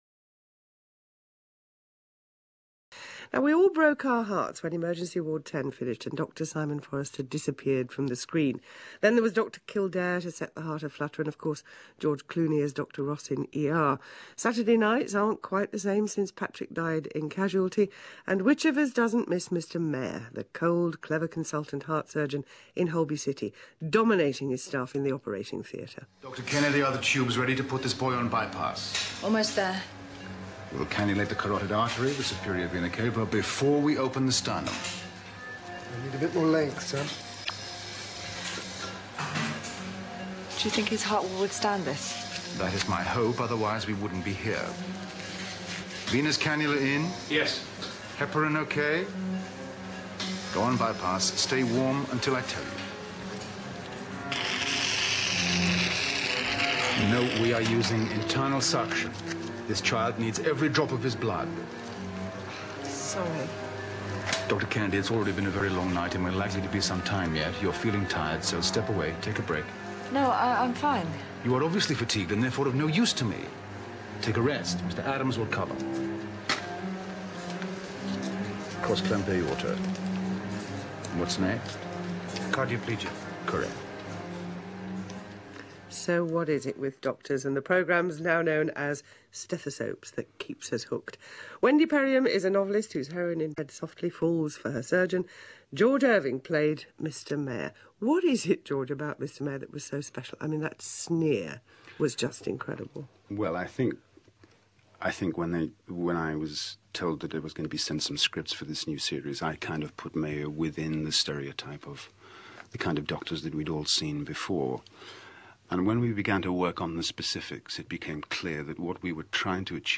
woman's hour interview